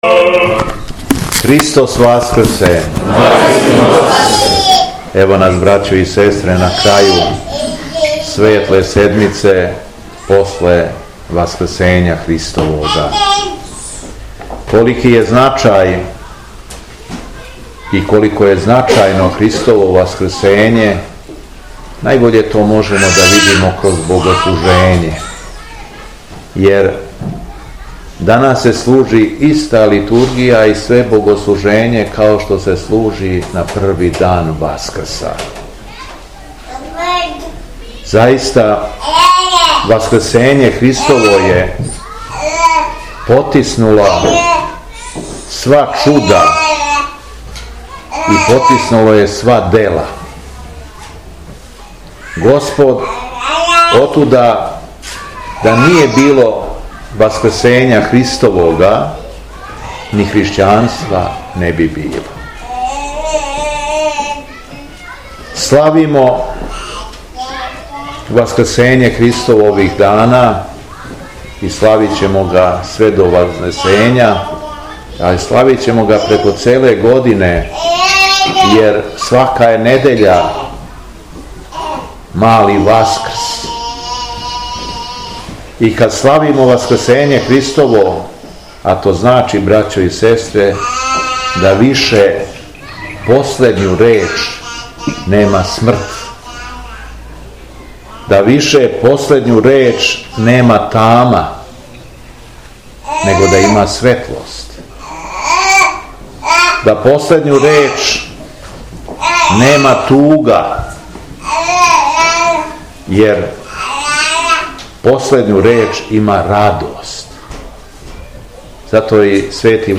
У светлу суботу Његово Преосвештенство епископ шумадијски Господин Јован служио је свету архијерејску Литургију у храму Свете Тројице у Селевцу.
Беседа Његовог Преосвештенства Епископа шумадијског г. Јована